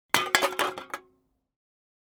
ゴミをつぶす・捨てる音_6（空き缶を捨てる音）